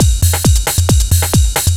DS 135-BPM A8.wav